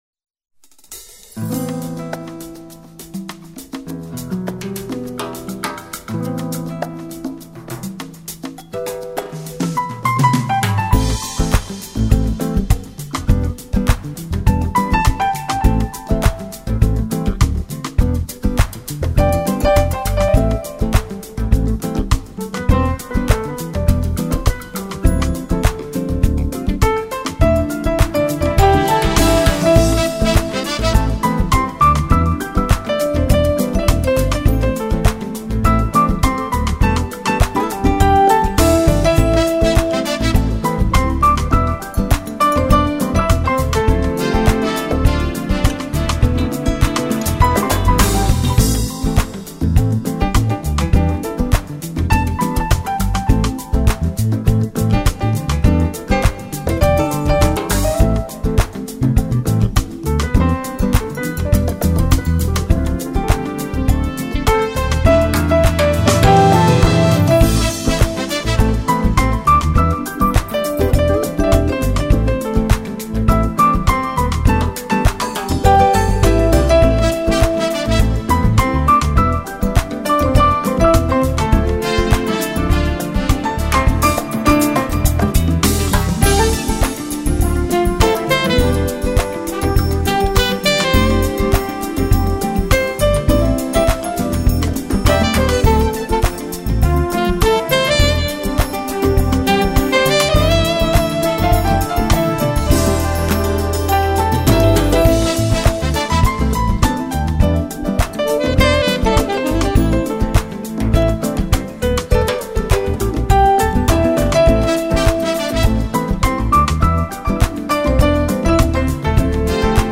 لایتموسیقی
[زبان موسیقی : لایت ( بدون کلام ) ]